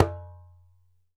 ASHIKO 4 01R.wav